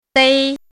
dēi
dei1.mp3